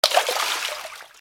/ M｜他分類 / L30 ｜水音-その他
水面を叩く 強 03